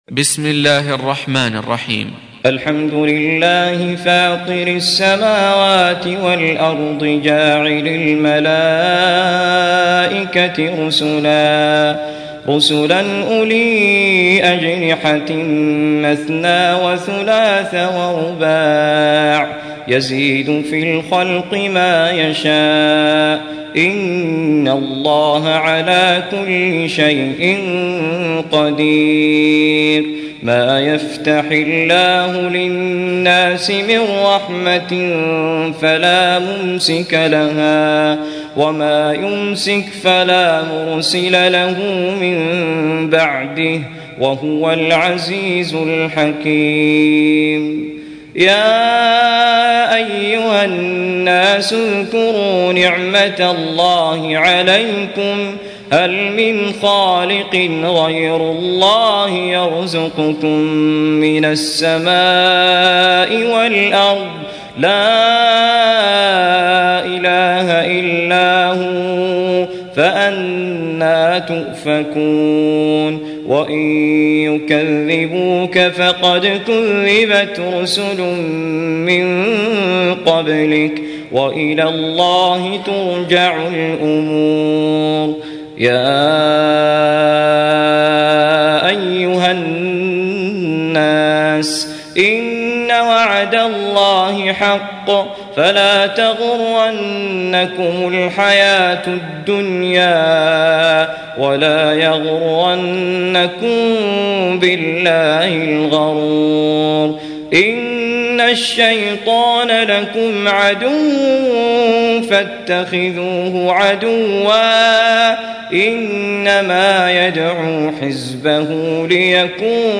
Audio Quran Tarteel Recitation
Surah Repeating تكرار السورة Download Surah حمّل السورة Reciting Murattalah Audio for 35. Surah F�tir or Al�Mal�'ikah سورة فاطر N.B *Surah Includes Al-Basmalah Reciters Sequents تتابع التلاوات Reciters Repeats تكرار التلاوات